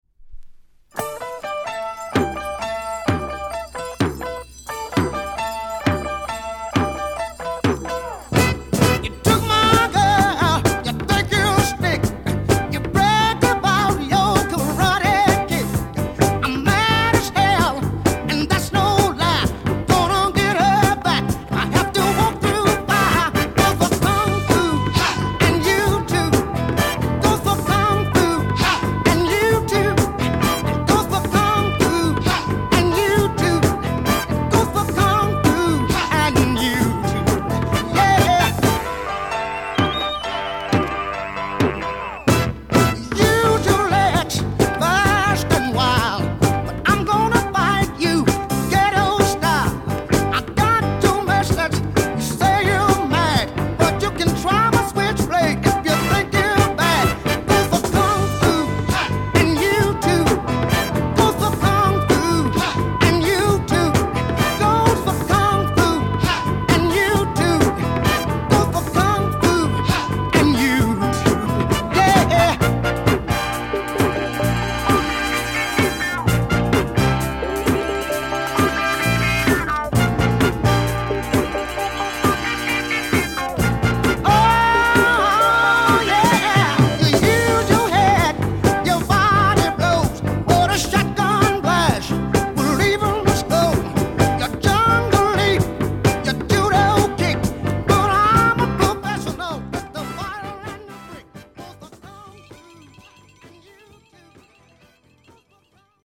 Nice mid 70s Funk single, rare French Picture sleeve.